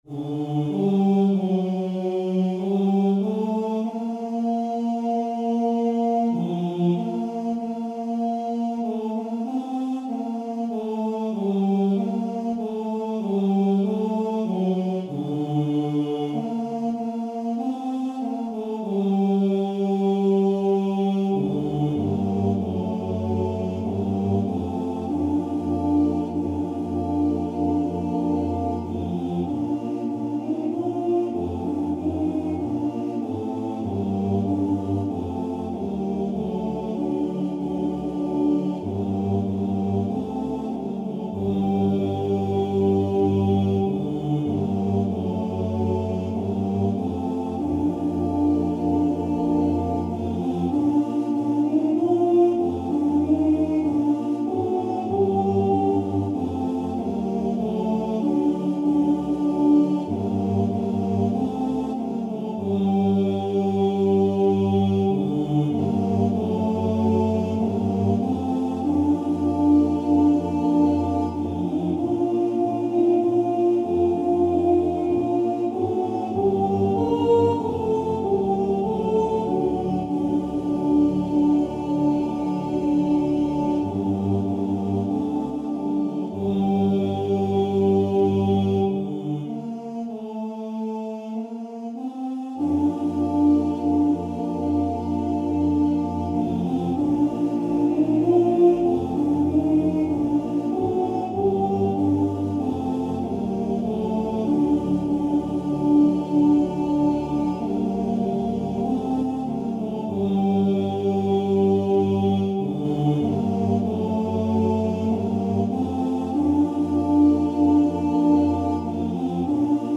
У ніч темную | Хор "ЧУМАКИ" :: Чоловічий гуртовий спів − Народні пісні у триголосному викладі
Комп'ютерне відтворення нот (mp3):